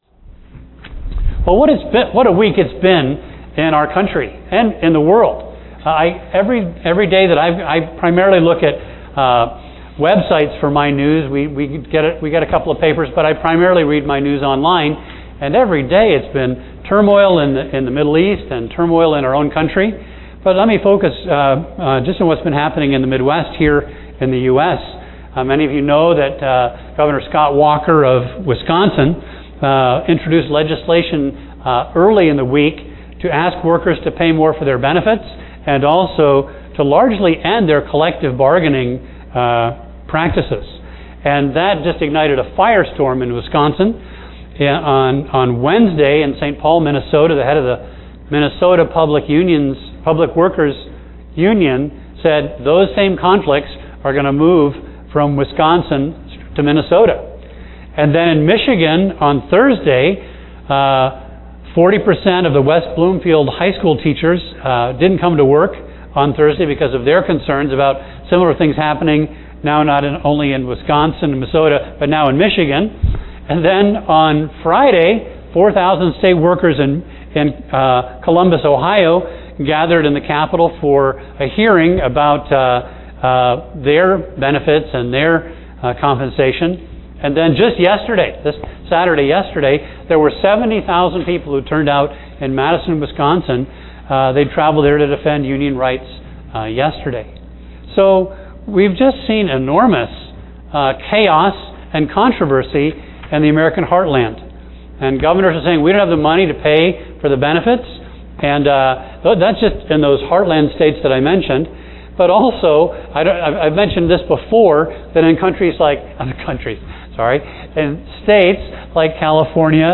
A message from the series "Elijah."